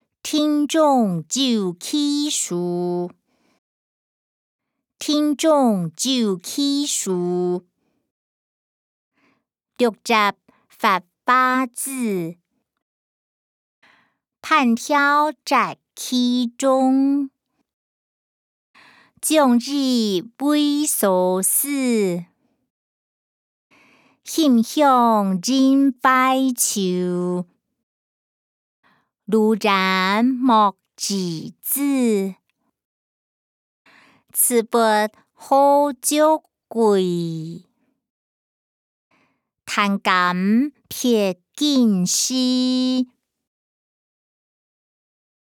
古典詩-庭中有奇樹音檔(海陸腔)